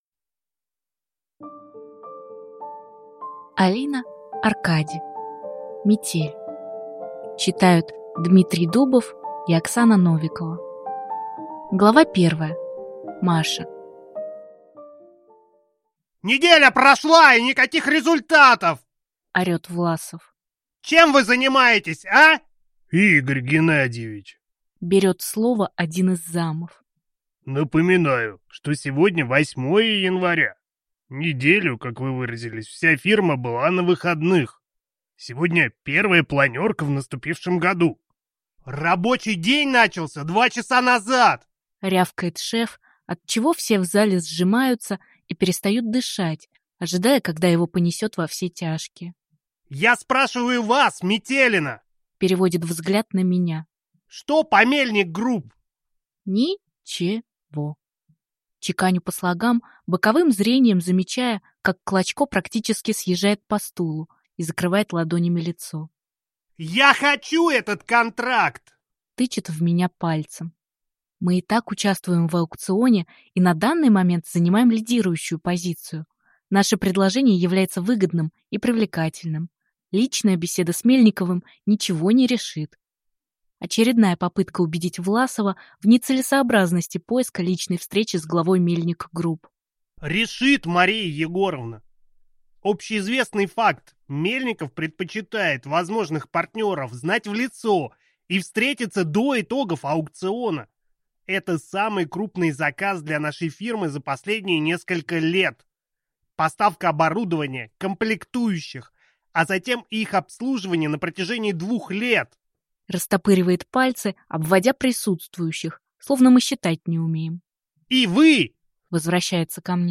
Аудиокнига Метель | Библиотека аудиокниг
Прослушать и бесплатно скачать фрагмент аудиокниги